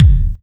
KICK38.wav